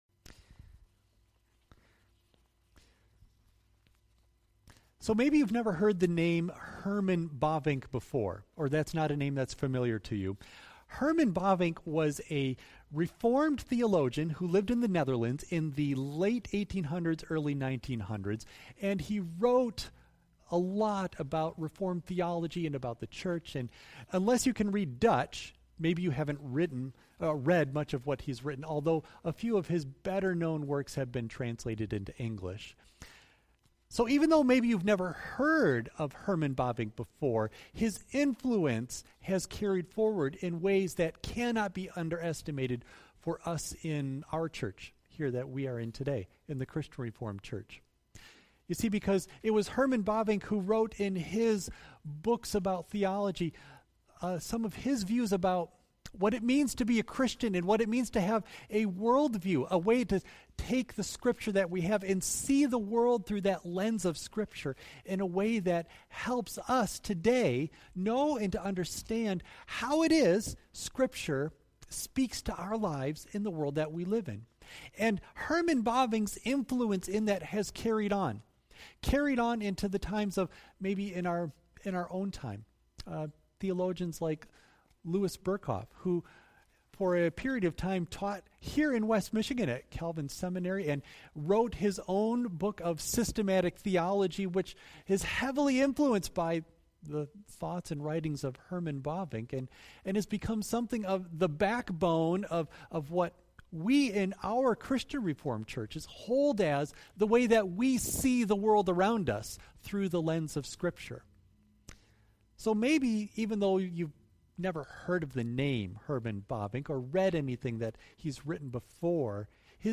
Worship Service June 21 Audio only of message